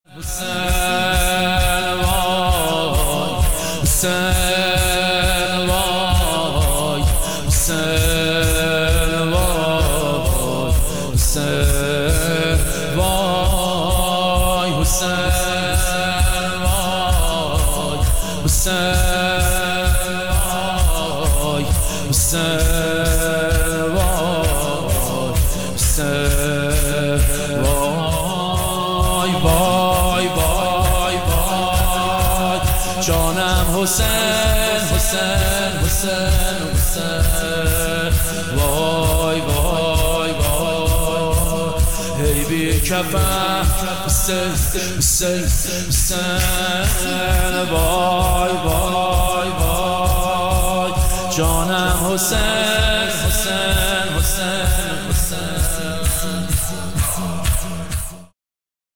ذکر
یادبود شهید حاج قاسم سلیمانی 13 دی 1403